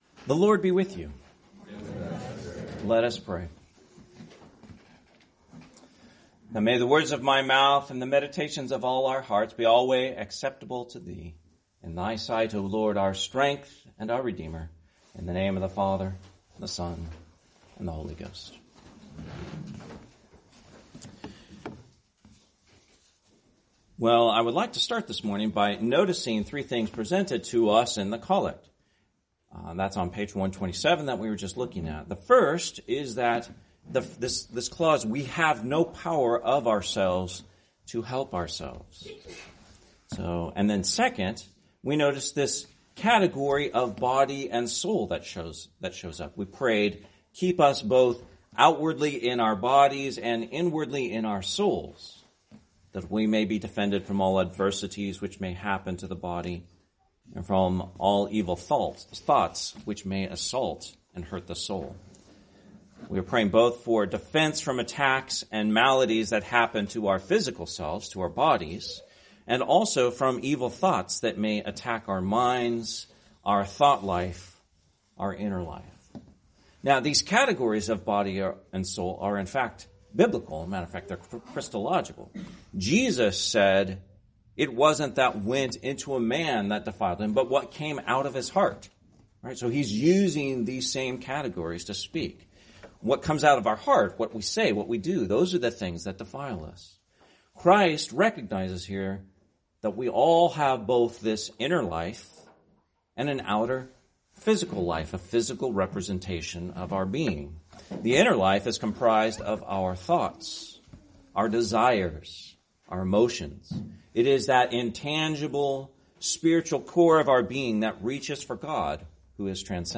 Sermon, 2nd Sunday in Lent, 2025